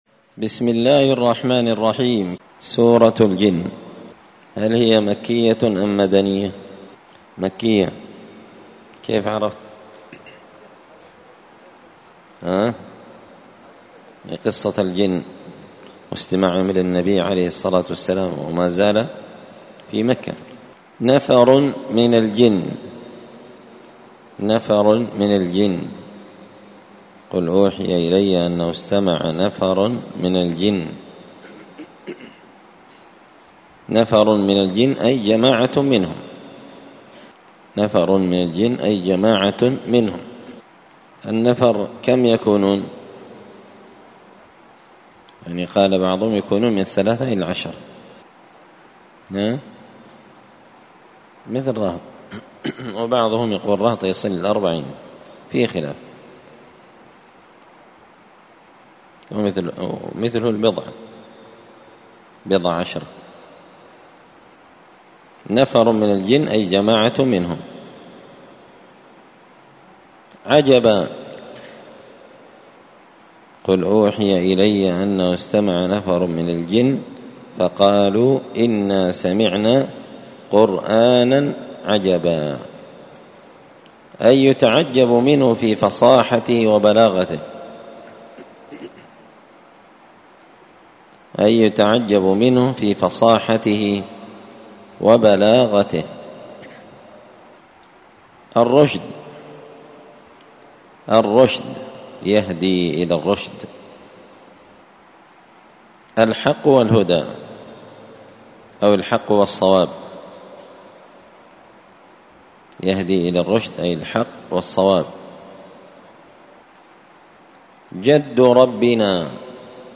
زبدة الأقوال في غريب كلام المتعال الدرس الثالث والثمانون (83)